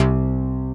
BASSFILT 2.wav